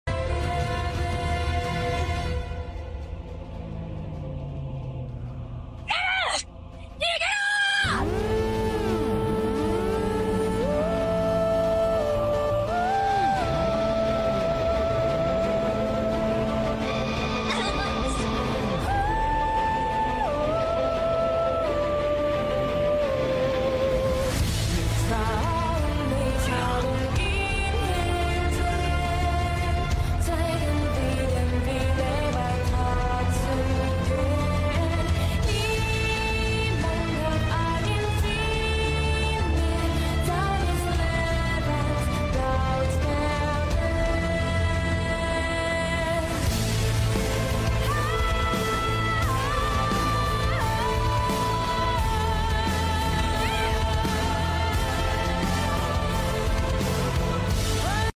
Genre: Anisongs • Orchestral
an epic and powerful track